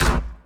BattleCatGrimeKick.wav